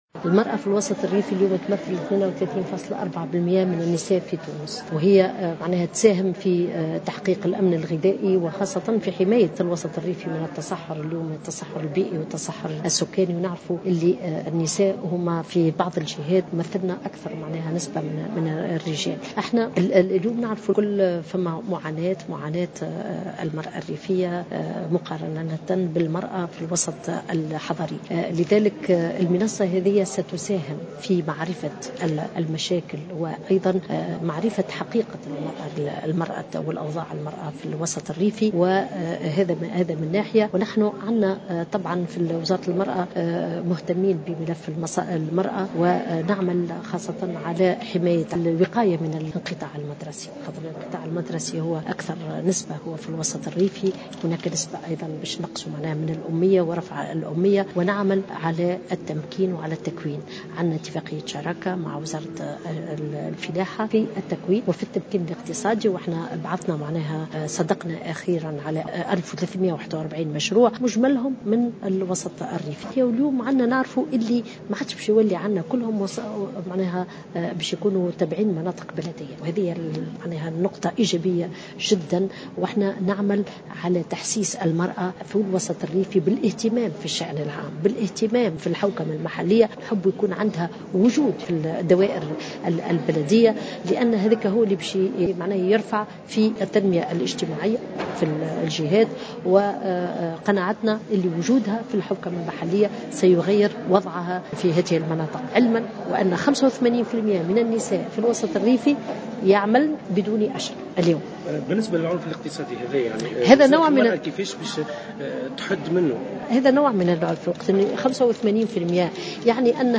وأضافت الوزيرة في تصريح لمراسل "الجوهرة أف أم" اليوم على هامش افتتاح المنصة الاقليمية للمرأة الريفية أن المرأة في الوسط الريفي تعمل بطريقة غير مهيكلة، بالرغم من أنها تمثل حوالي 78 بالمائة من اليد العاملة في القطاع الفلاحي.